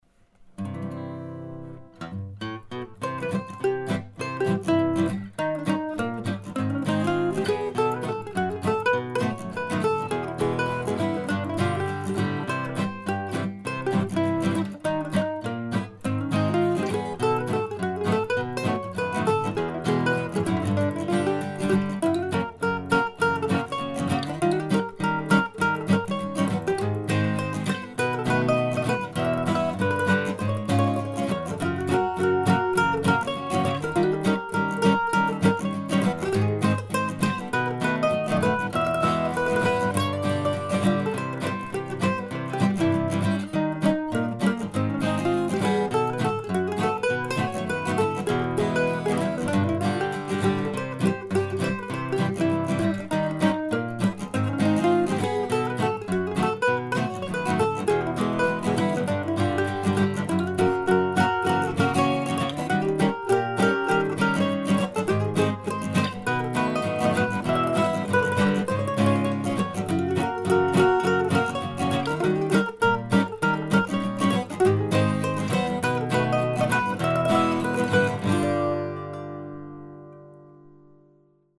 This tune is an attempt to write a fun contra dance number that incorporates some elements of Coltrane's approach to harmony during his "Giant Steps" period.
The recording here is at a comfortable tempo but still contains no attempt to improvise over the chord changes.